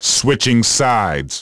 Siege_Switching.WAV